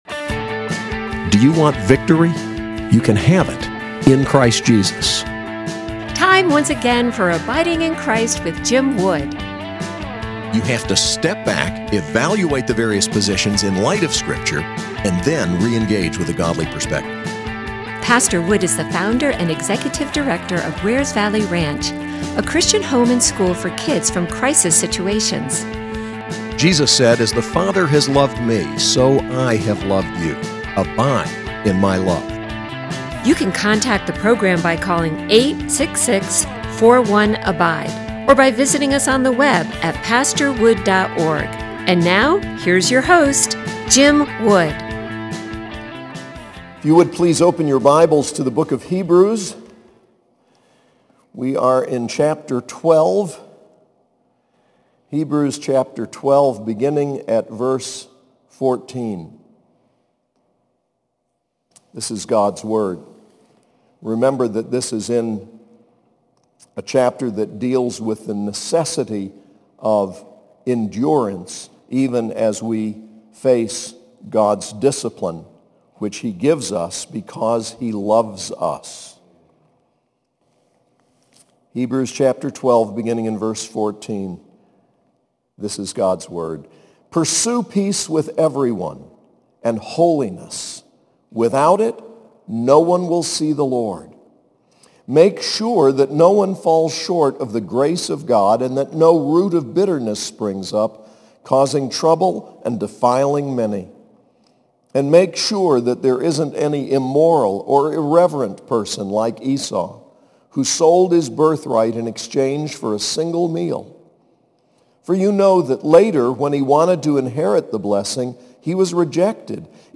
SAS Chapel: Hebrews 12:14-29